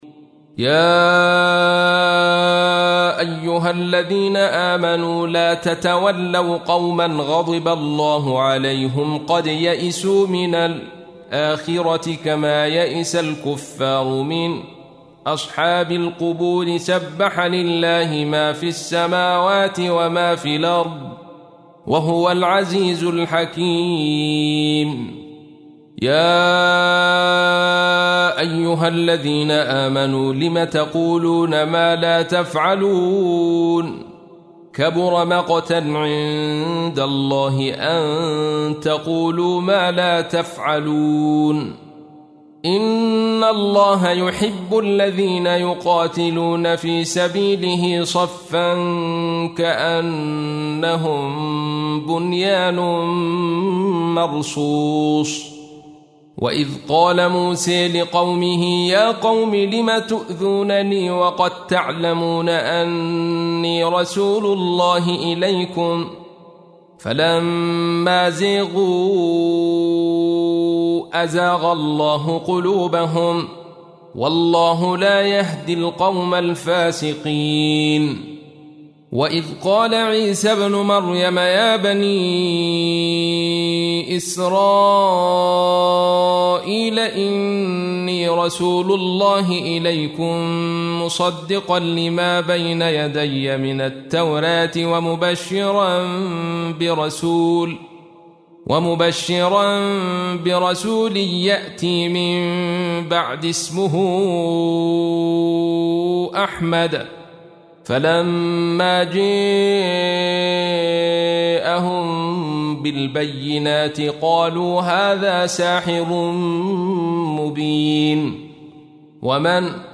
تحميل : 61. سورة الصف / القارئ عبد الرشيد صوفي / القرآن الكريم / موقع يا حسين